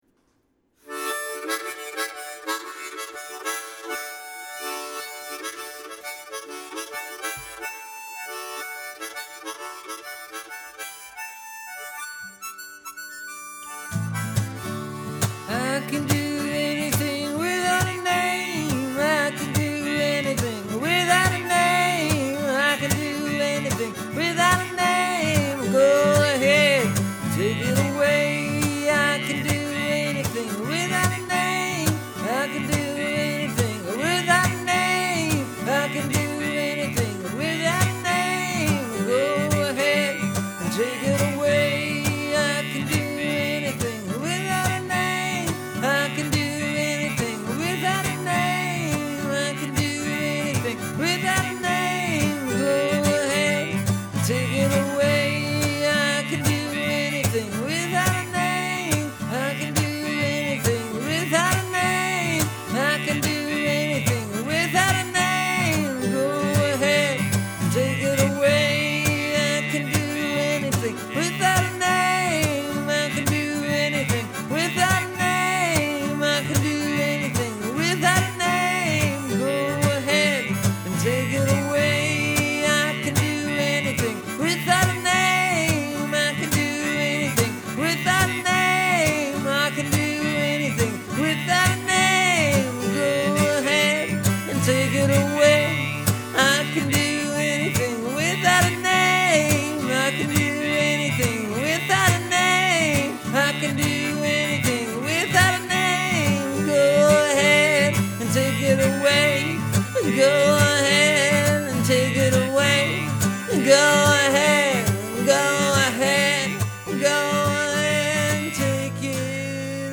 I recorded the harp first for this one.
Then I put in some guitar.
They I added two tracks of vocals after coming up with a line of words.
(Please listen close on the vocal track. Do you hear that? That’s either my voice going because I’m out of practice, or because I’m coming down with a head cold. Your pick.)